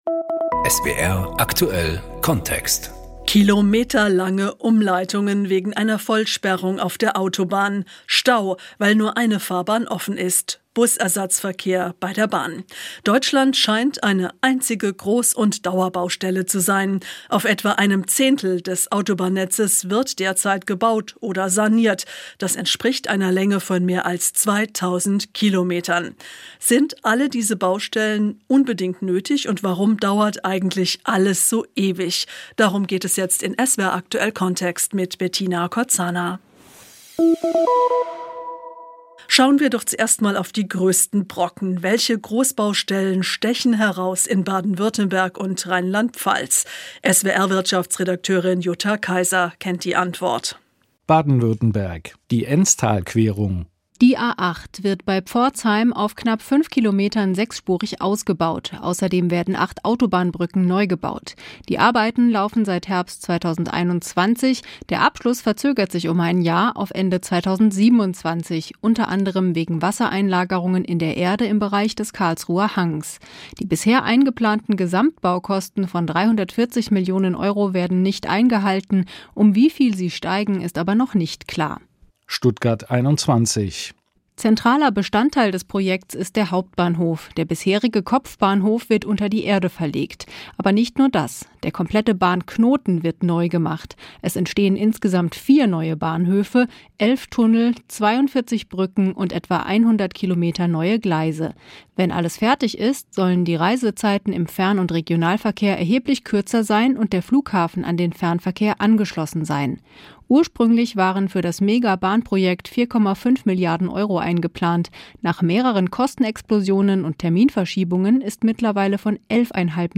Wir sprechen mit Fachleuten und schauen uns in der deutschen Baustellen-Landschaft um.